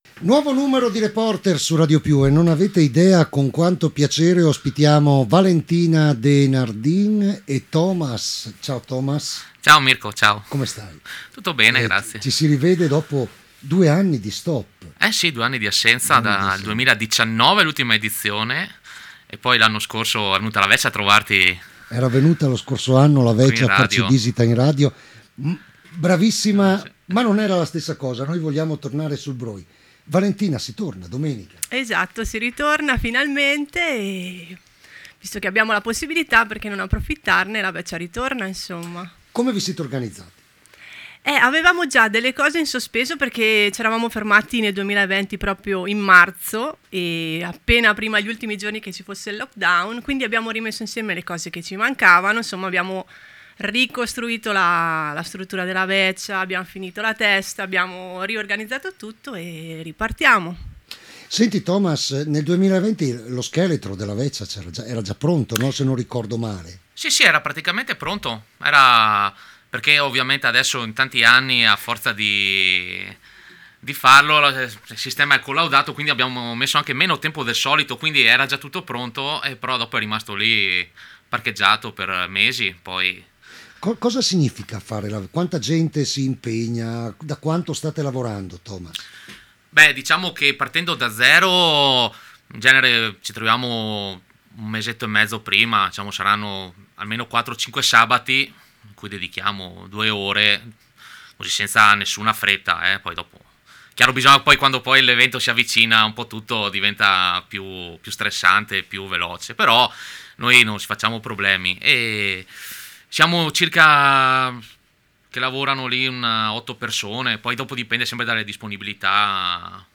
ieri alla radio ospiti in studio